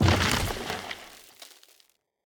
car-wood-impact-05.ogg